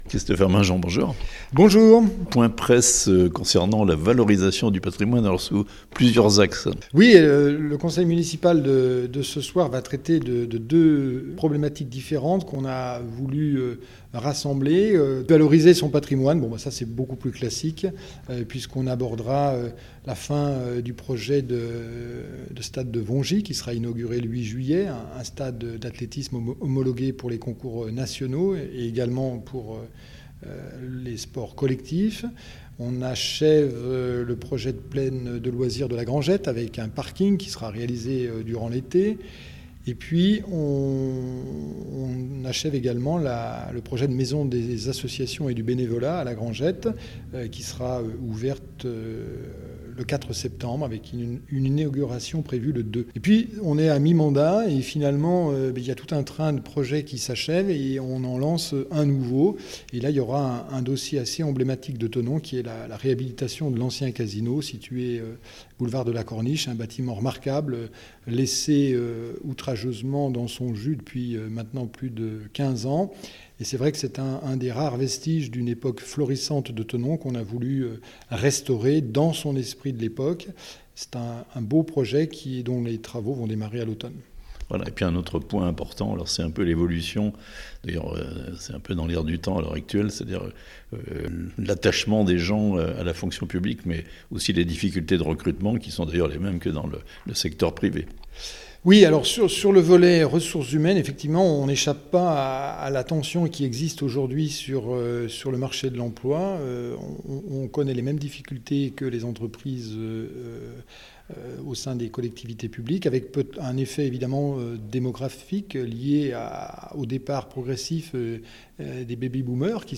Thonon : présentation de l'ordre du jour du conseil municipal du lundi 19 juin (interview)